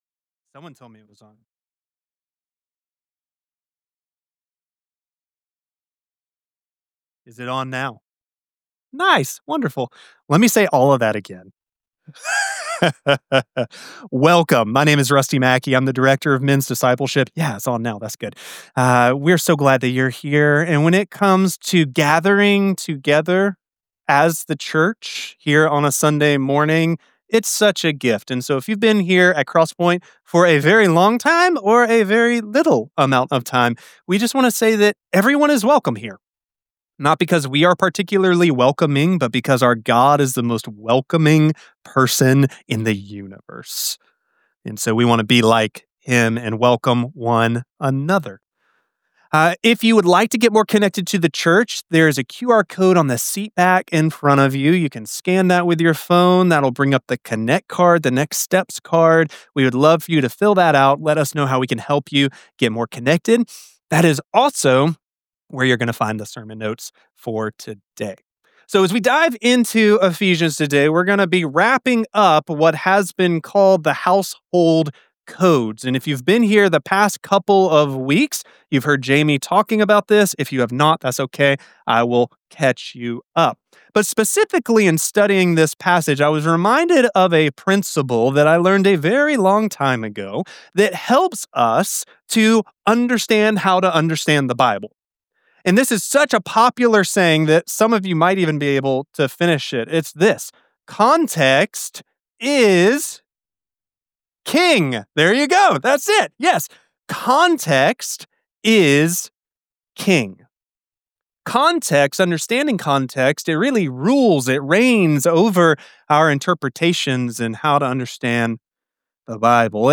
6.22.25 Sermon Only- mastered.mp3